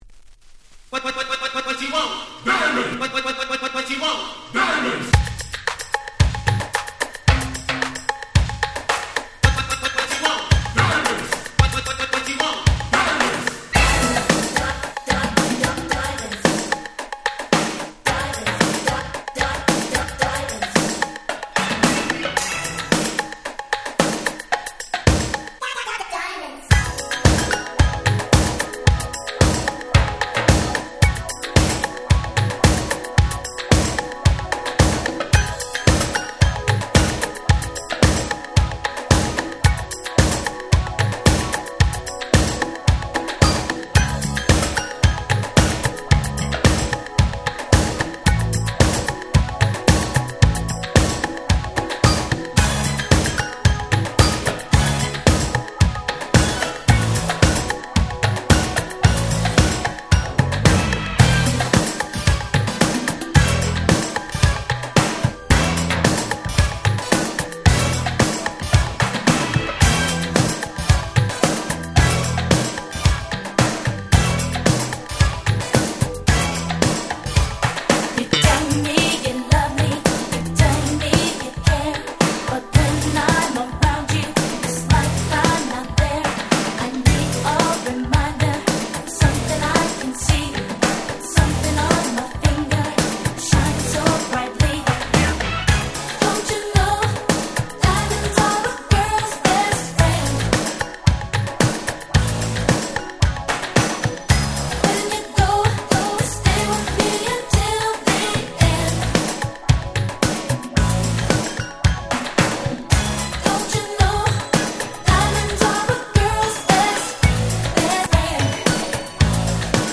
80's R&B Super Classics !!
New Jack Swing